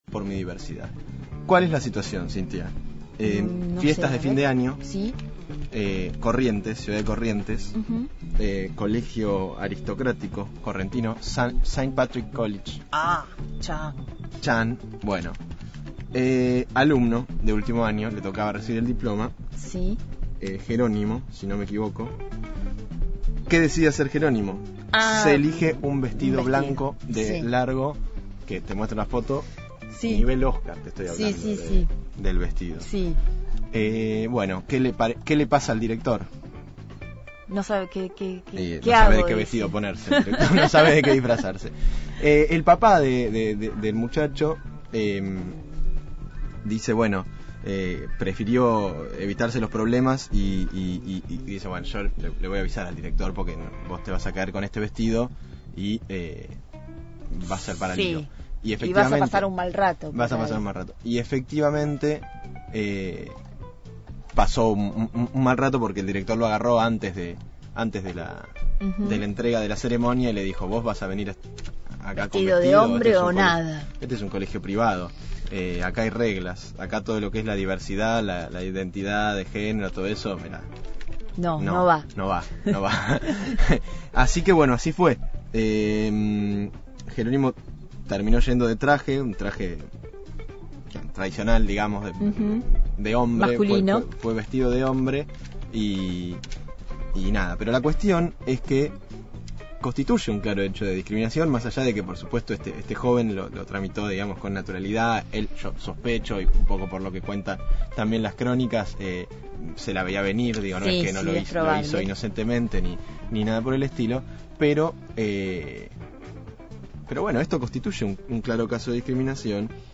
Columna sobre medios de comunicación